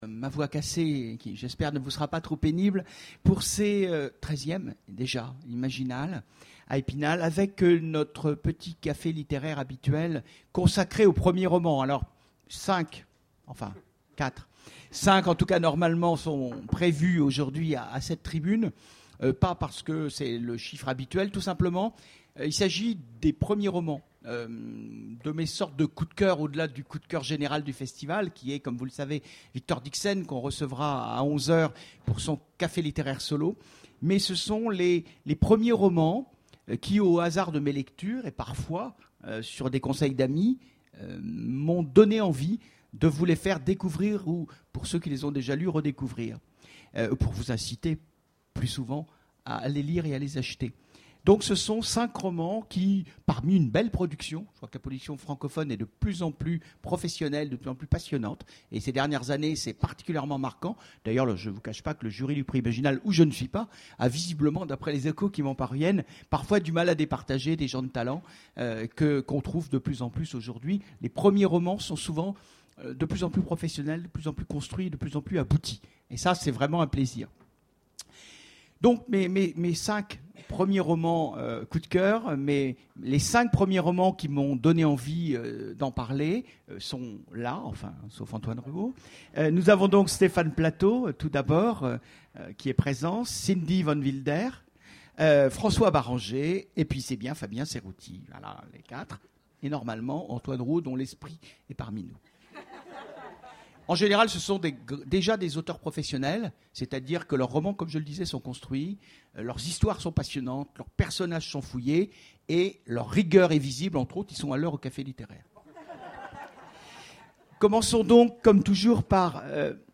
Imaginales 2014 : Conférence Premiers romans, nouveaux talents...